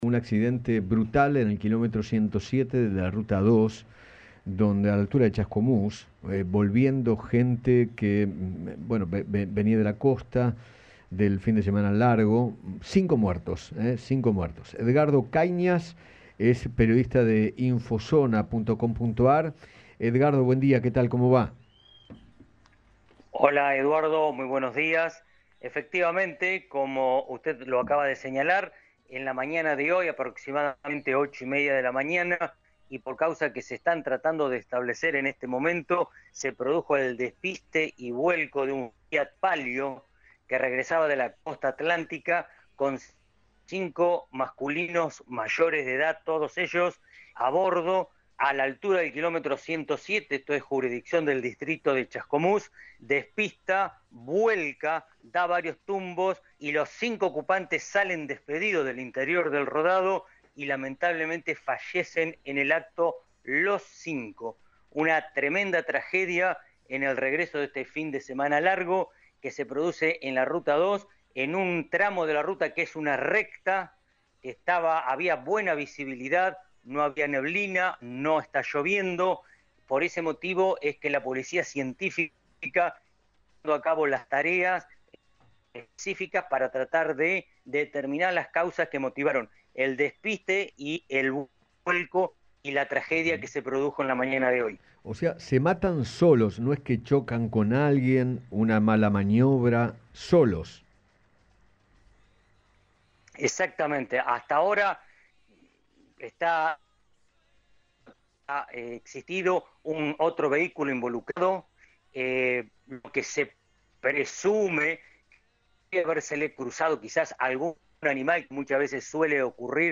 Accidente-fatal-en-la-Ruta-2-hay-cinco-muertos-Radio-Rivad.mp3